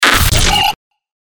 FX-1506-BREAKER
FX-1506-BREAKER.mp3